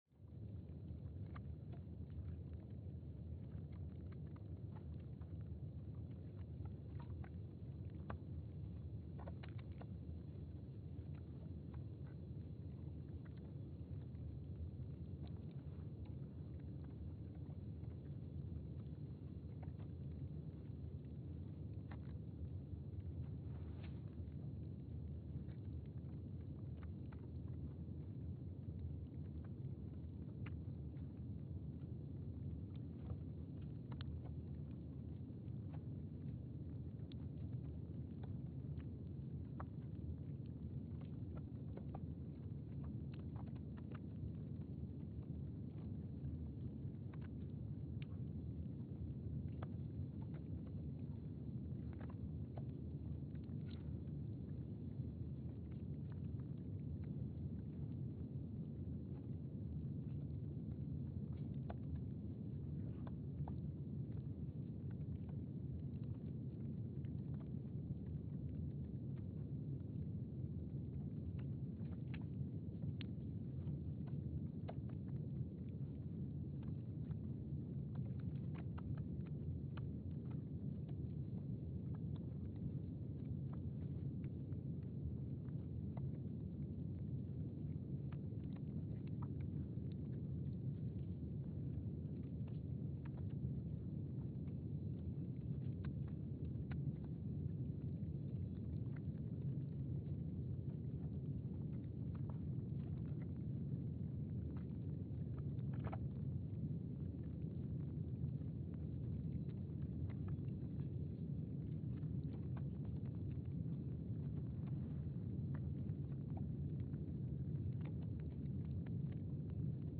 Station : PMSA (network: IRIS/USGS) at Palmer Station, Antarctica
Sensor : STS-1VBB_w/E300
Speedup : ×500 (transposed up about 9 octaves)
Gain correction : 25dB
SoX post-processing : highpass -2 90 highpass -2 90